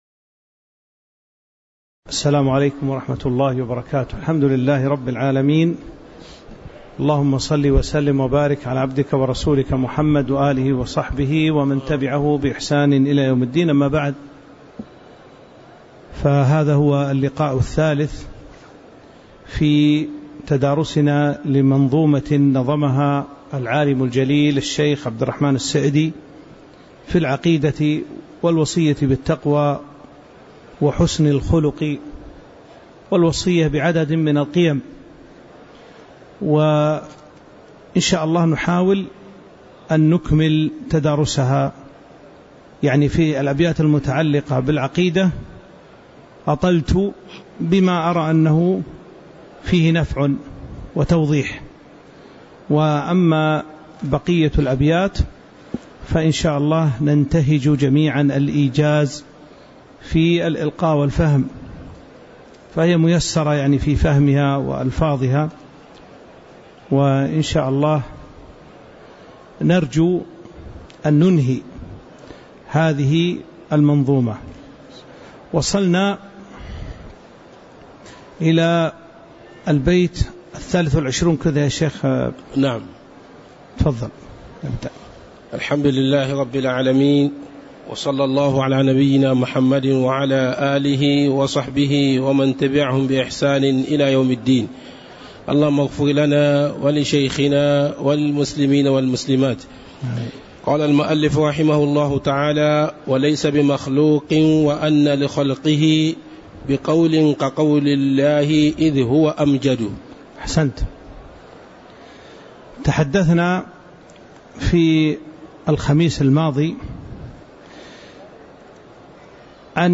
تاريخ النشر ١٤ محرم ١٤٤٦ هـ المكان: المسجد النبوي الشيخ